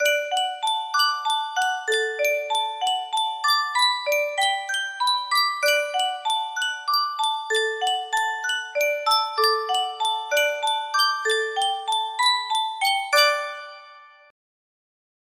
Sankyo Music Box - O Holy Night XQ music box melody
Full range 60